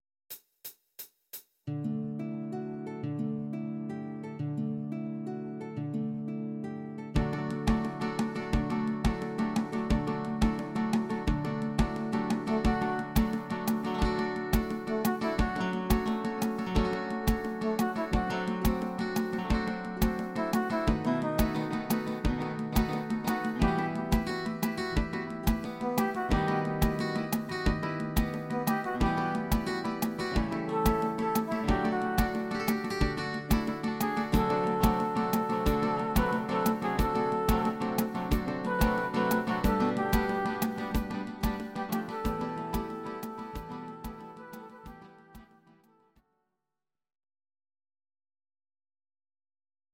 transposed minus 6